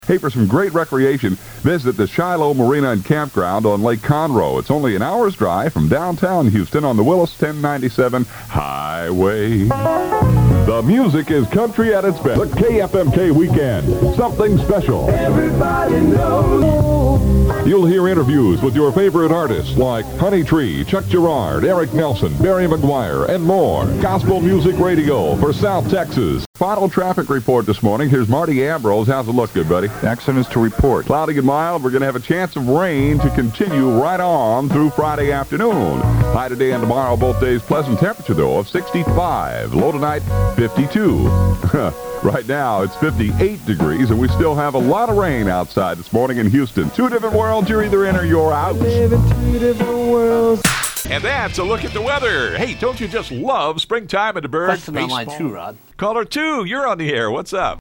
Male
English (North American)
Commercial, Guy next door, Bright, Believable, Confident, Friendly, Warm, Deep, Informative, Authoritative, Happy, Mature
Live Announcer
Radio Air Check Fun